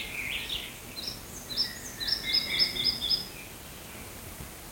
Birds -> Flycatchers ->
Red-breasted Flycatcher, Ficedula parva
StatusSinging male in breeding season